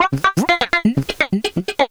Index of /90_sSampleCDs/Houseworx/12 Vocals